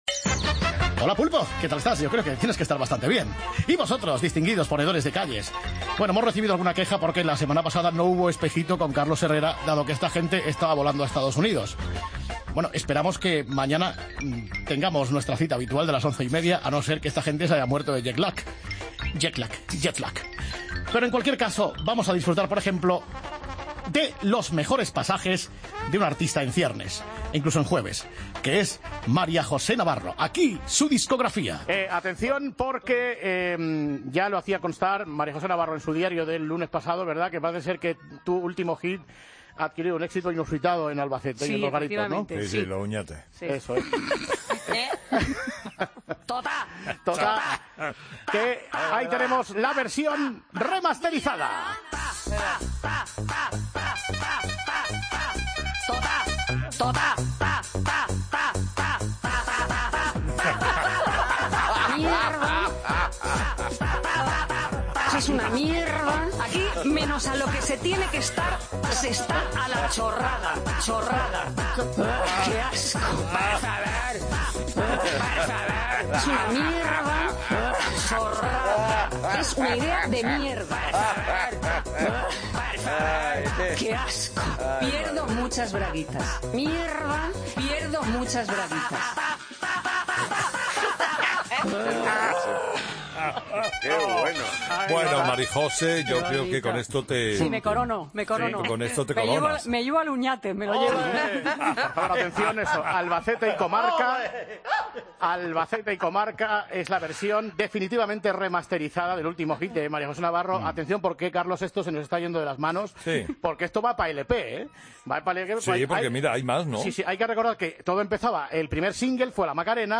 Vuelven para ofrecernos otro momento musical sin parangón.